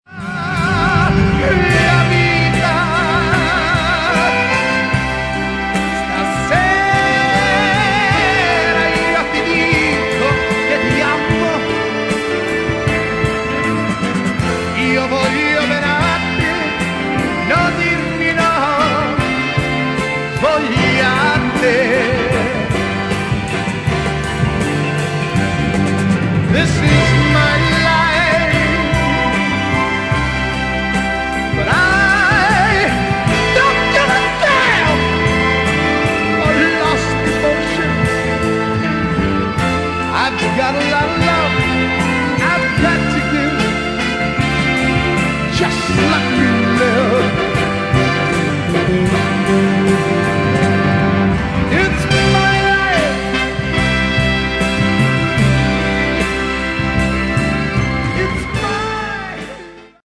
the extraordinary voice and mellow stylings